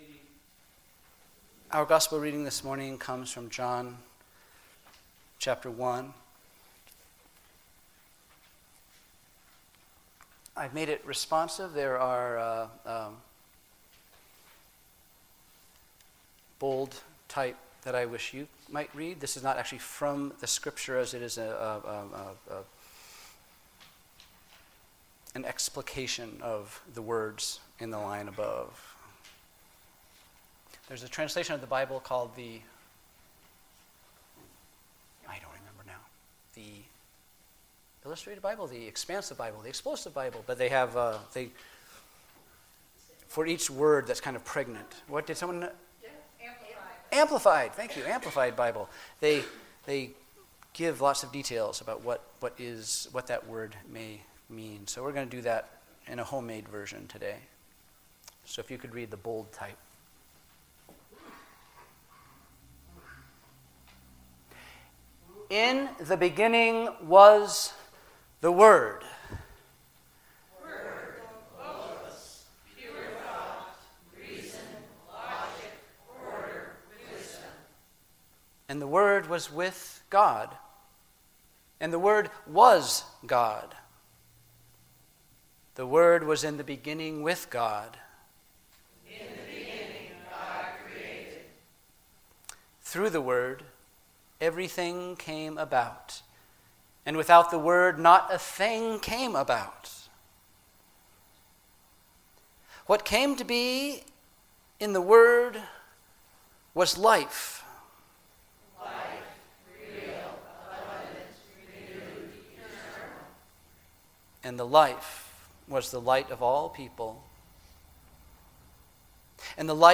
Sermons What Is God Really Like?